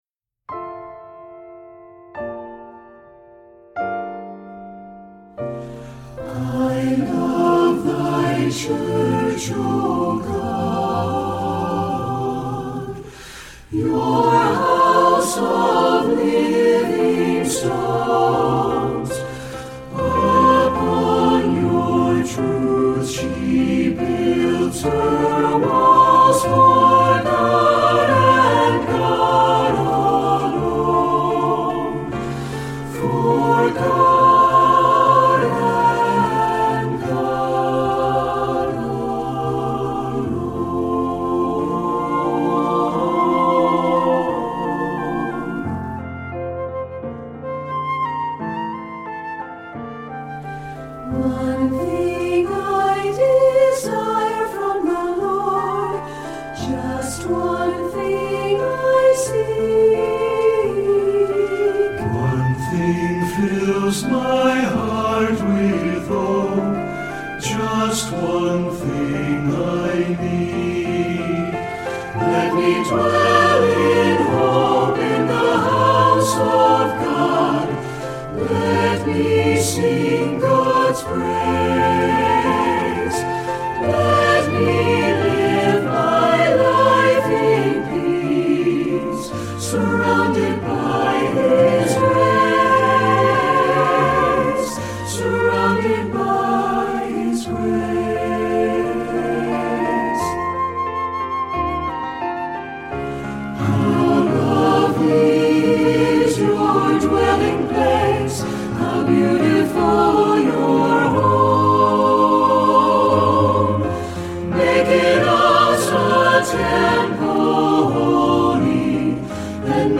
Voicing: SATB and Flute